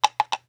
SFX_pasosCaballo3.wav